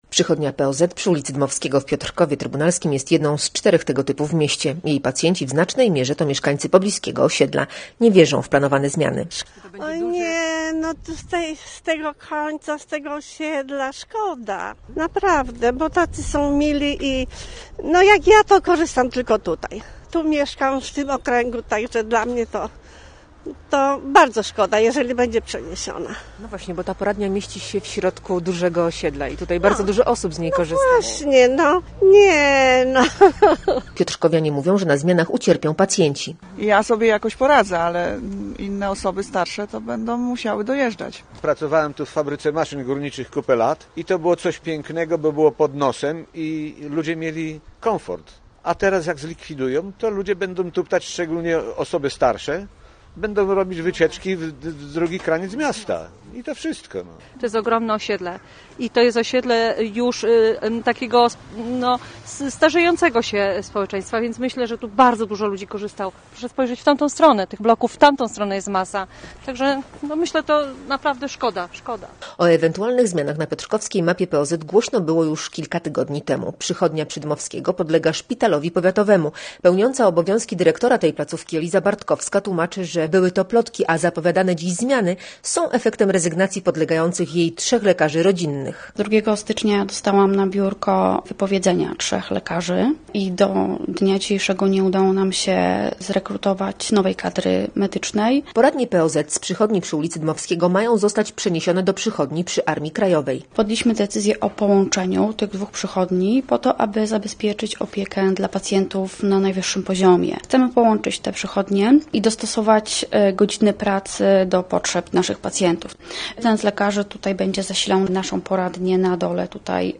Posłuchaj relacji: Nazwa Plik Autor Przychodnia w Piotrkowie audio (m4a) audio (oga) Warto przeczytać Pogoda na piątek.